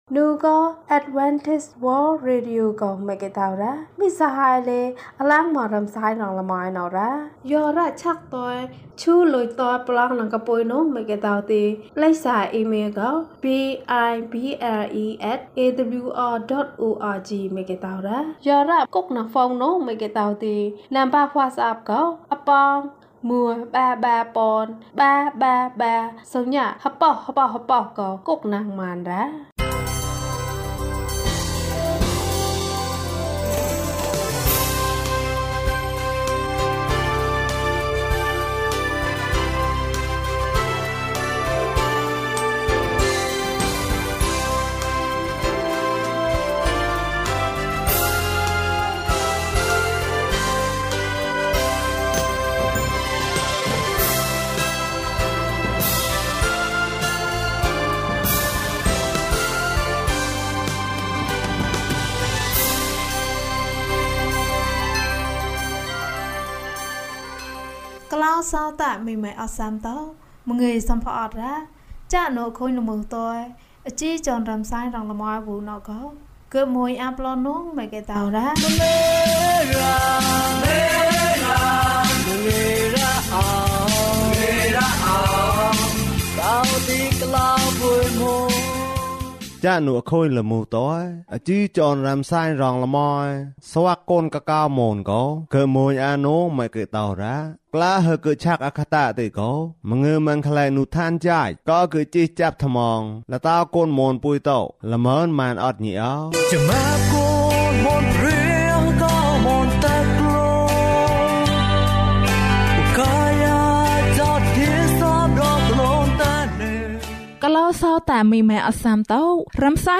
ခရစ်တော်ထံသို့ ခြေလှမ်း။၅၄ ကျန်းမာခြင်းအကြောင်းအရာ။ ဓမ္မသီချင်း။ တရားဒေသနာ။